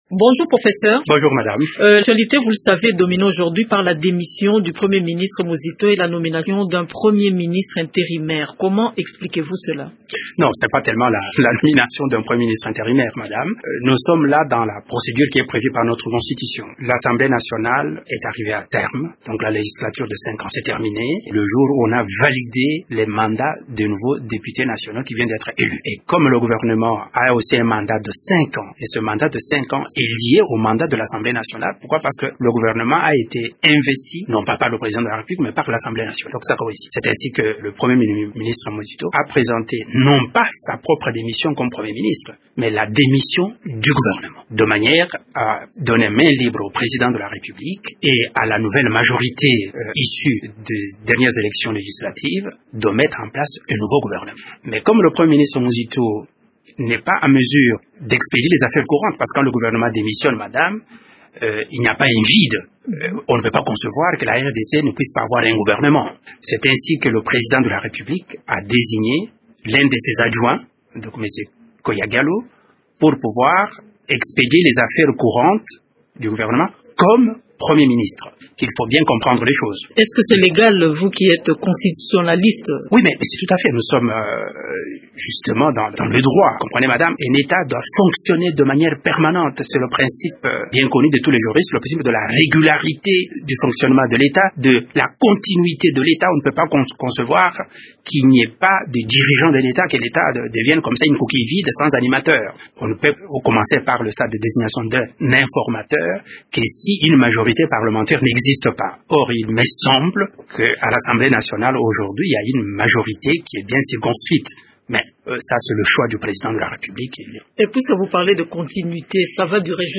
Il est interrogé